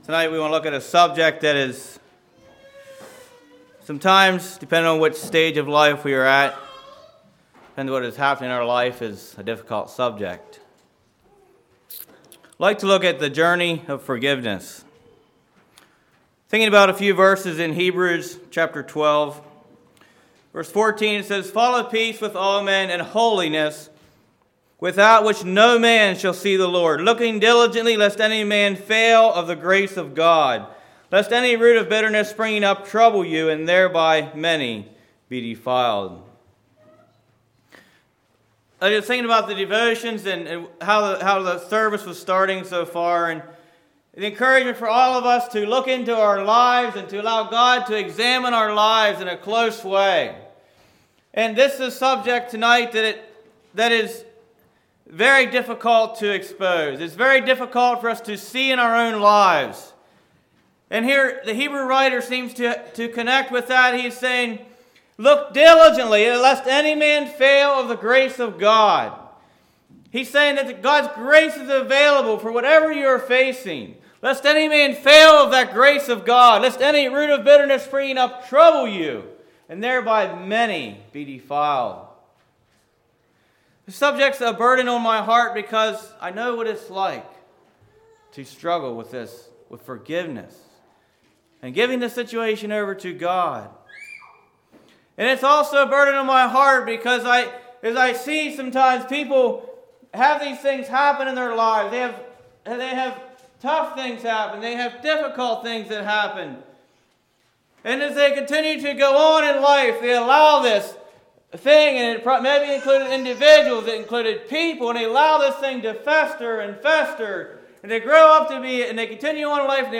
2016 Sermon ID